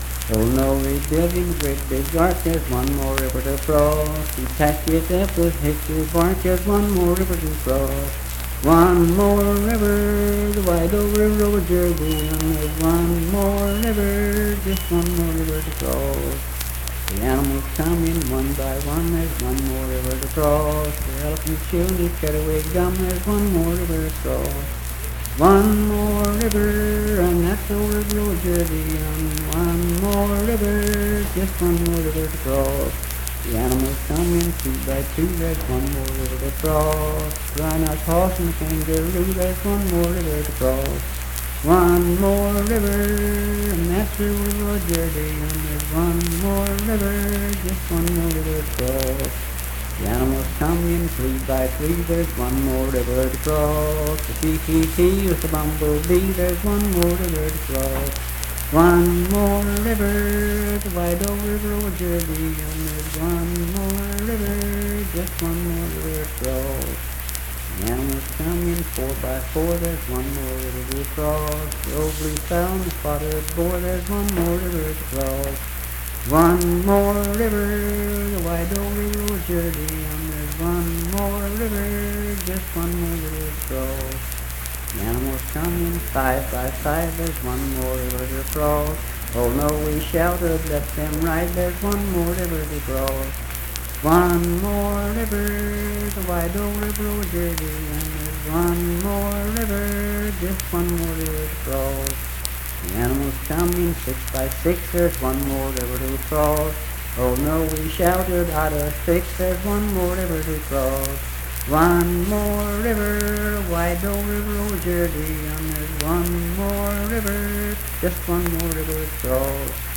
Unaccompanied vocal music performance
Hymns and Spiritual Music
Voice (sung)
Calhoun County (W. Va.)